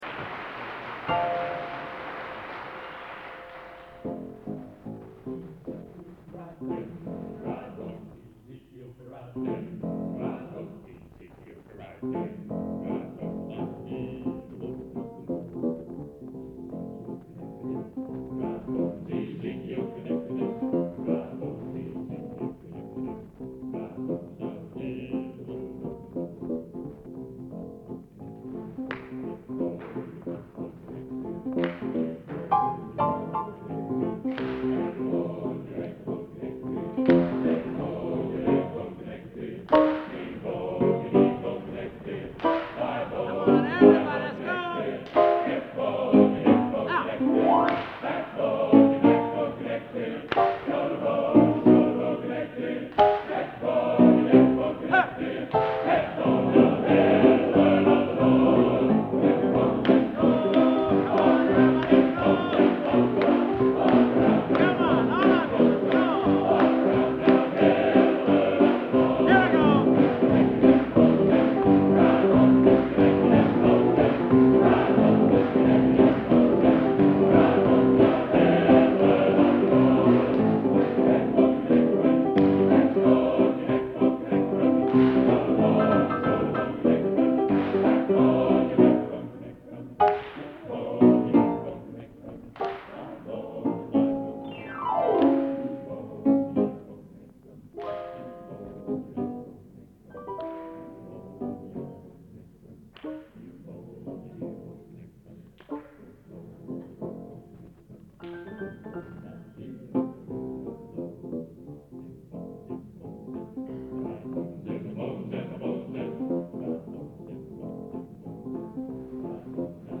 Location: Plymouth, England